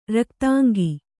♪ raktāngi